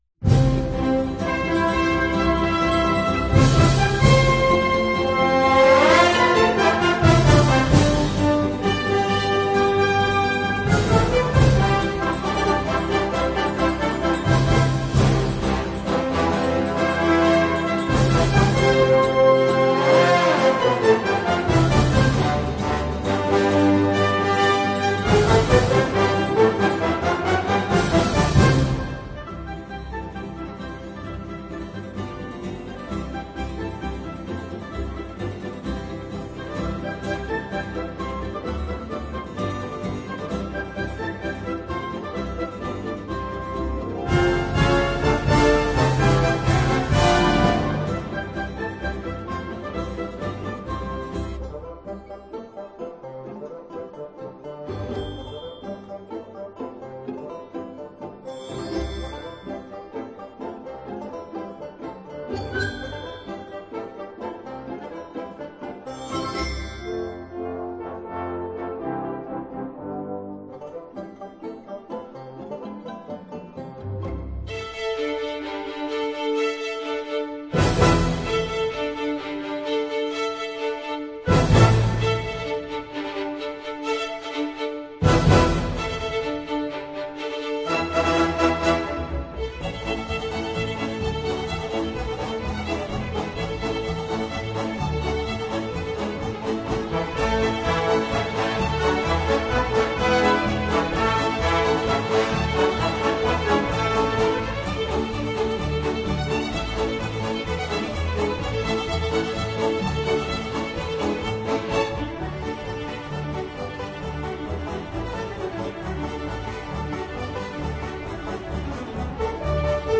用交响乐队来表现民歌
鸡鸣狗叫，马嘶牛鸣。一派乡土气息。